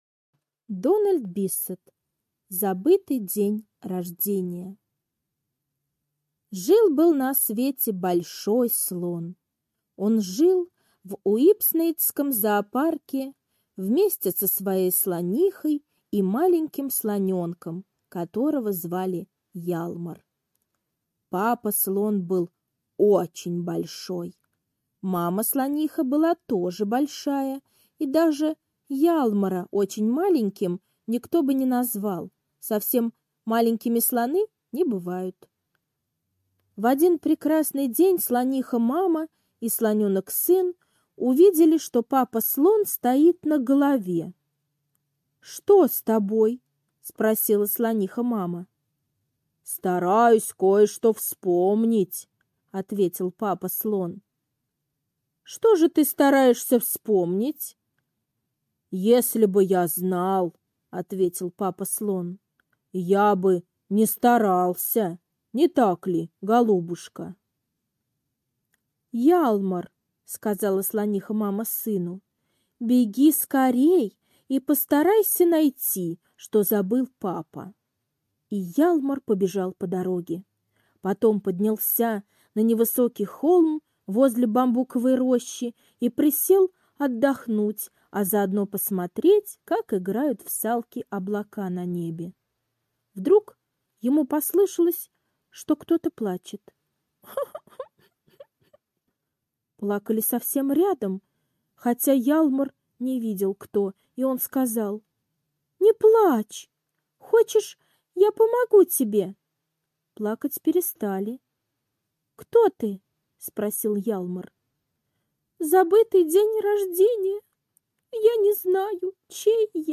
Аудиосказка «Забытый день рождения»